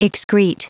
Prononciation du mot excrete en anglais (fichier audio)
Prononciation du mot : excrete